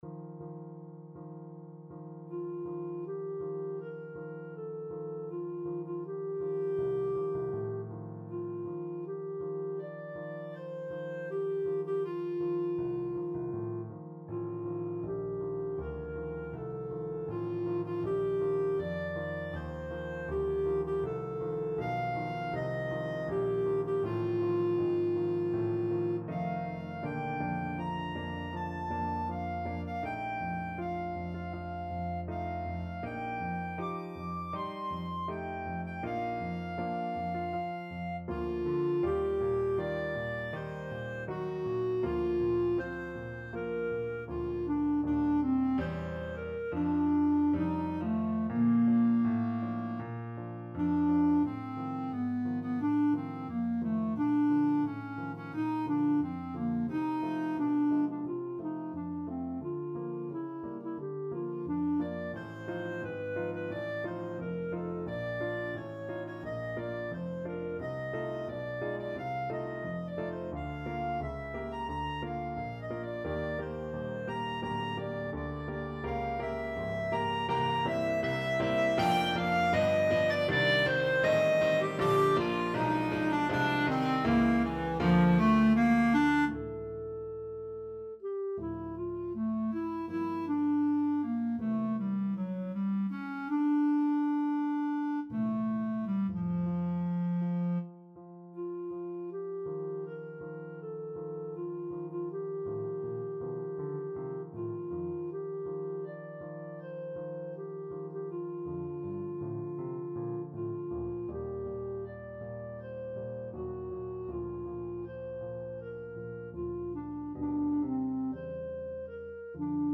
Free Sheet music for Clarinet
Clarinet
4/4 (View more 4/4 Music)
Andante espressivo
Bb major (Sounding Pitch) C major (Clarinet in Bb) (View more Bb major Music for Clarinet )
Classical (View more Classical Clarinet Music)